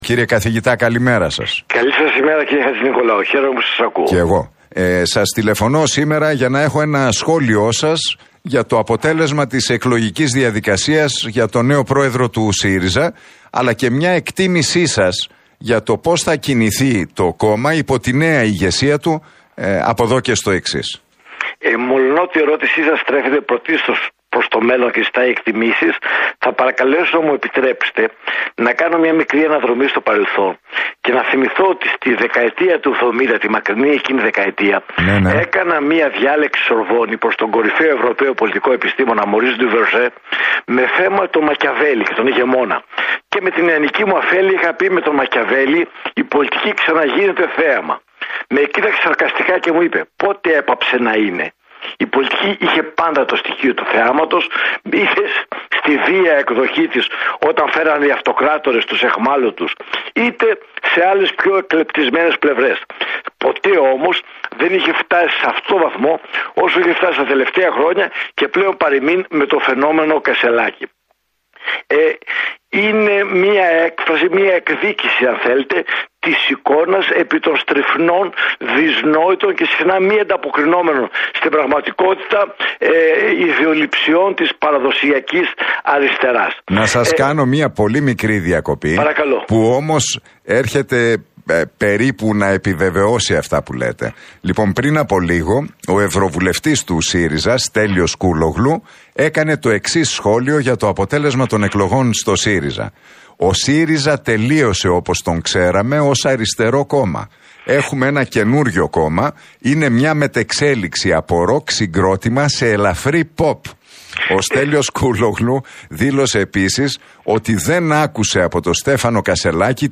μιλώντας στον Realfm 97,8 και στην εκπομπή του Νίκου Χατζηνικολάου